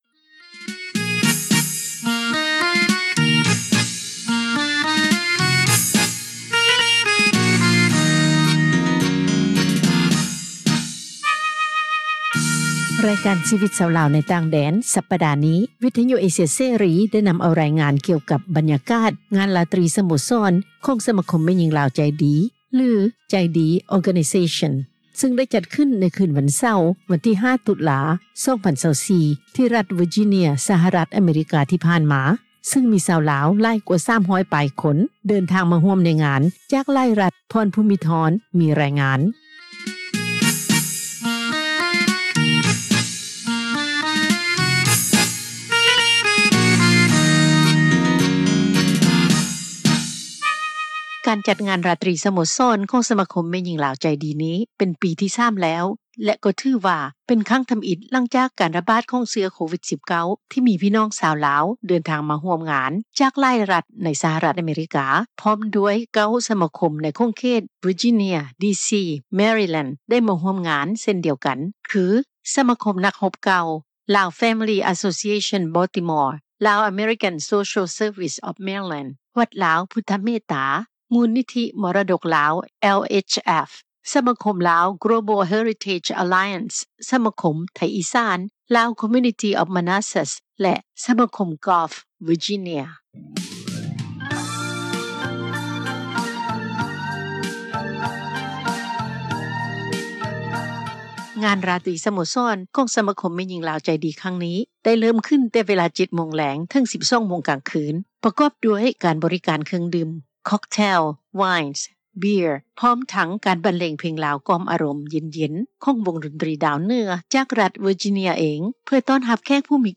ບັນຍາກາດ ງານຣາຕຣີ ສະໂມສອນ ຄັ້ງທີ່ 3 ຂອງສະມາຄົມ ແມ່ຍິງລາວ ໃຈດີ, ຊຶ່ງໄດ້ຈັດຂຶ້ນ ໃນຄືນ ວັນເສົາ ວັນທີ່ 05 ຕຸລາ 2024 ທີ່ ຣັດເວິຈີເນັຽ ສະຫະຣັດ ອາເມຣິກາ ທີ່ຜ່ານມາ, ຊຶ່ງມີຊາວລາວ 300 ປາຍຄົນ ເຂົ້າຮ່ວມ, ຮວມທັງ ຊາວລາວເດີນທາງມາ ຈາກຫລາຍຣັດ ໃນສະຫະຣັດ ອາເມຣິກາ.